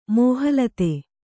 ♪ mōha late